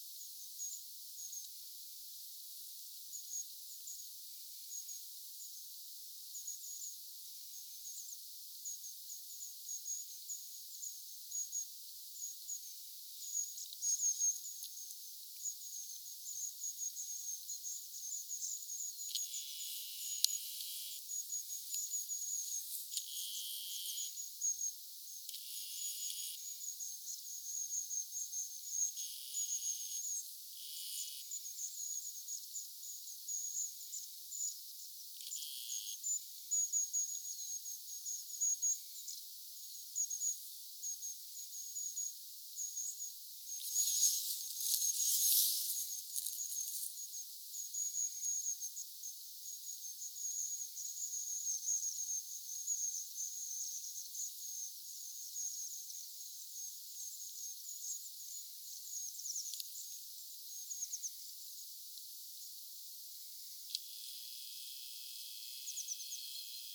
pyrstötiaisparven ääntelyä
pyrstotiaisparven_aantelya.mp3